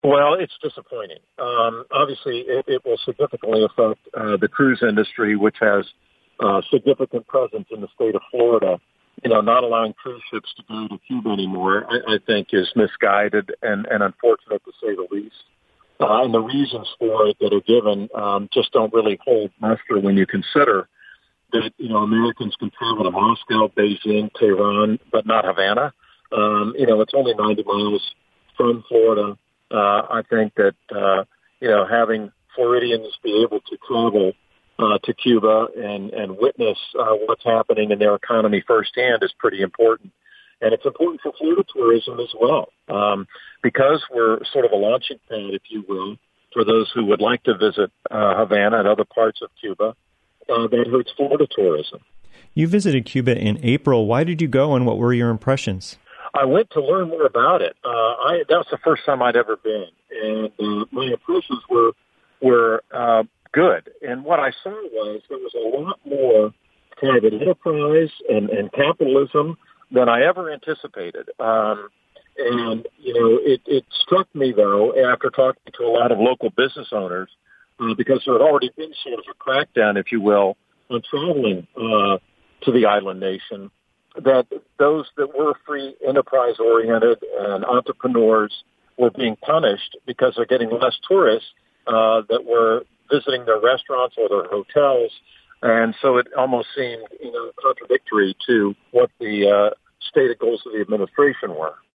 For reaction, WMNF asked Democratic Congress member Charlie Crist, who recently visited Cuba.